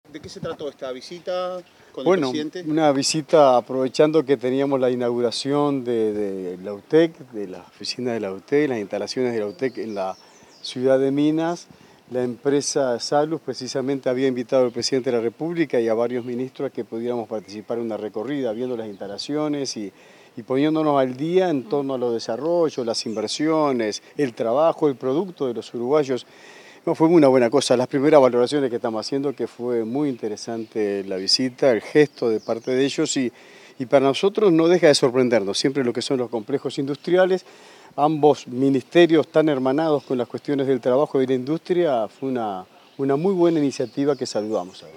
Declaraciones de ministro de Trabajo, Juan Castillo